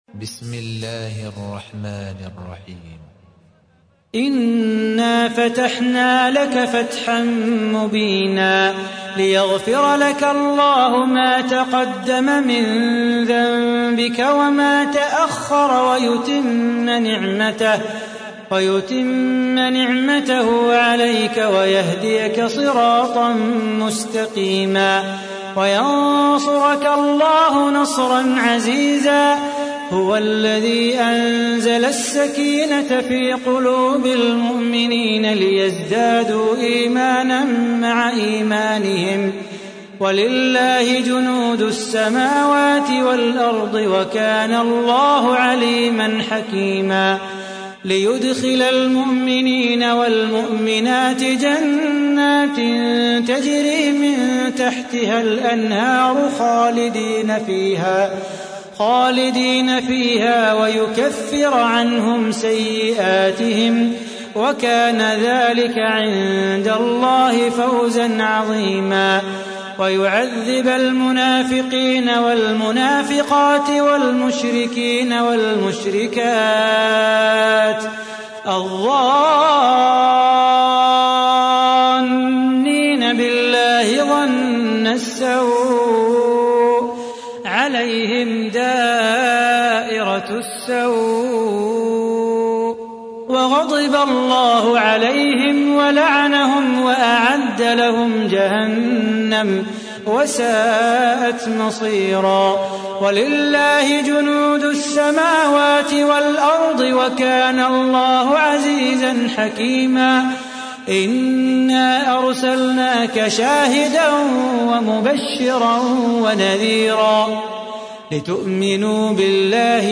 تحميل : 48. سورة الفتح / القارئ صلاح بو خاطر / القرآن الكريم / موقع يا حسين